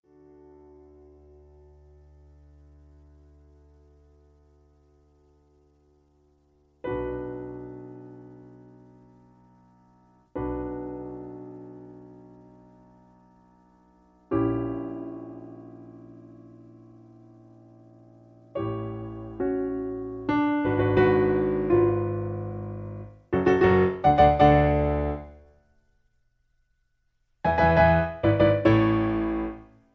Original Key (D). Piano Accompaniment